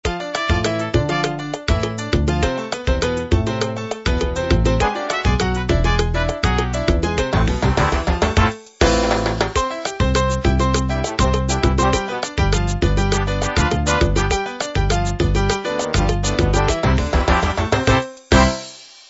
mp3 demo LATIN - LatinJAZZ - POP